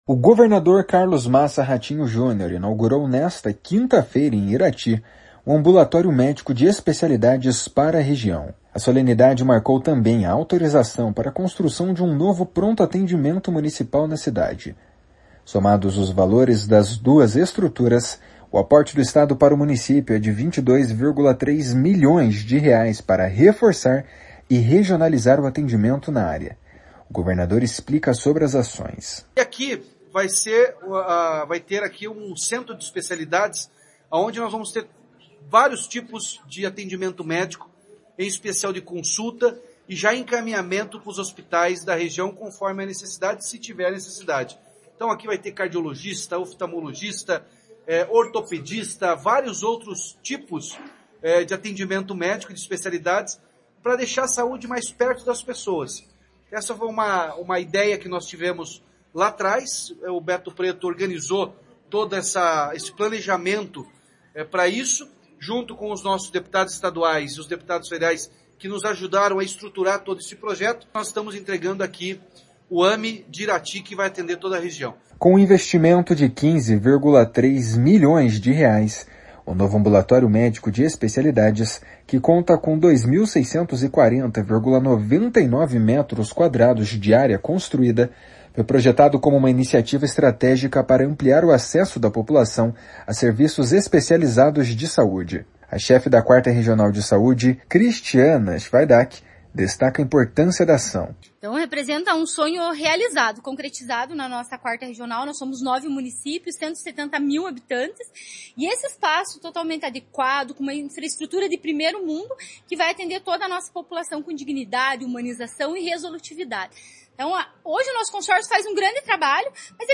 O governador explica sobre as ações. // SONORA RATINHO JUNIOR //
O prefeito de Irati, Emiliano Gomes, comemora o ganho da população.